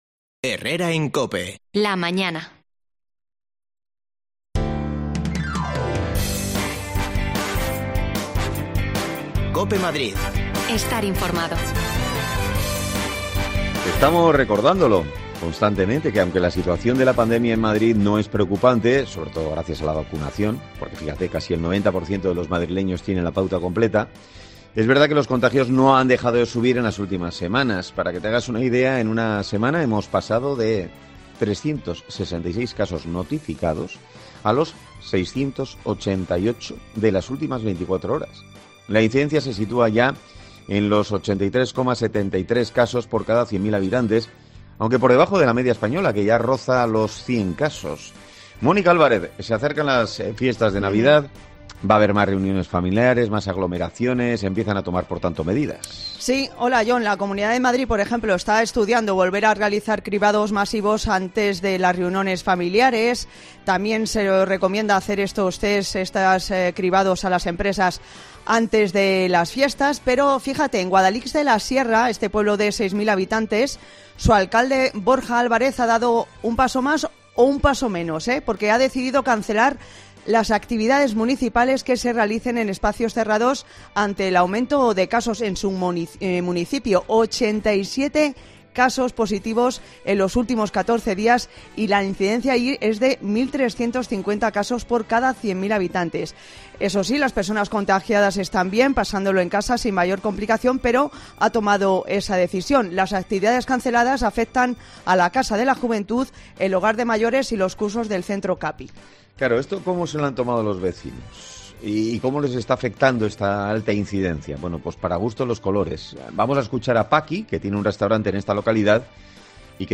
Hablamos con los vecinos
Las desconexiones locales de Madrid son espacios de 10 minutos de duración que se emiten en COPE , de lunes a viernes.